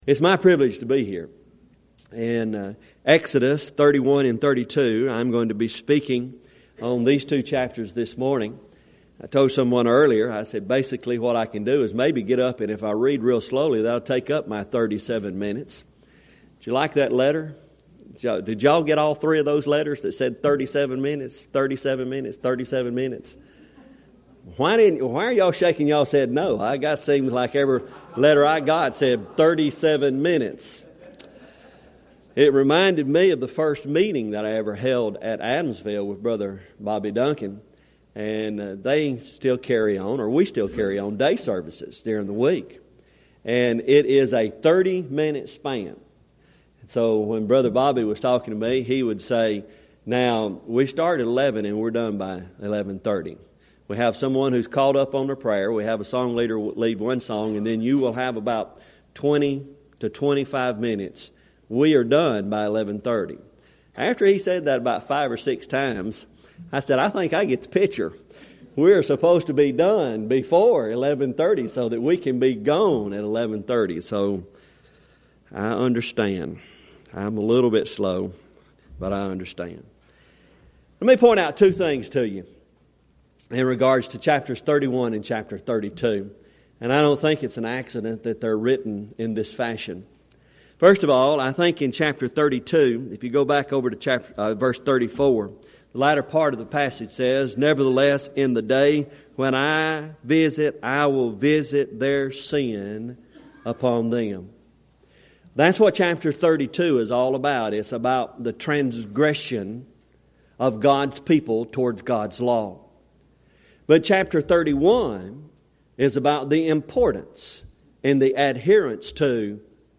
Event: 2nd Annual Schertz Lectures
lecture